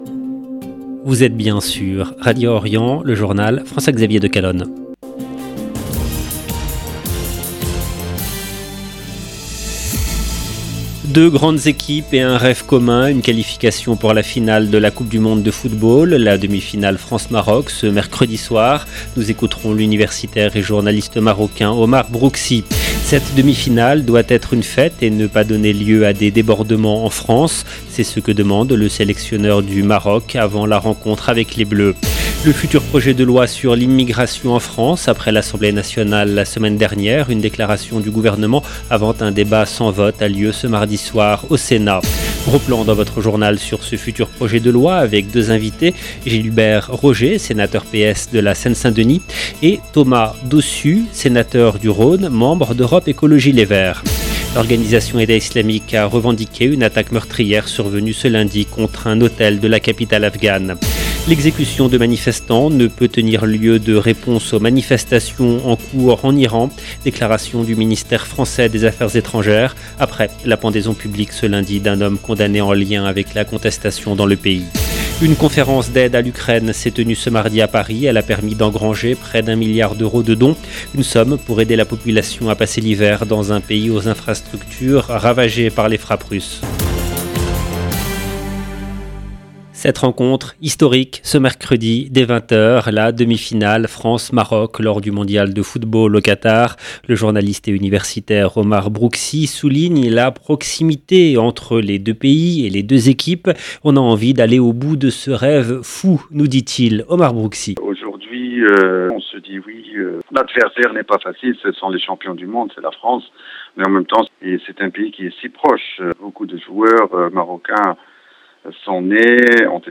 LE JOURNAL EN LANGUE FRANCAISE DU SOIR DU 13/12/22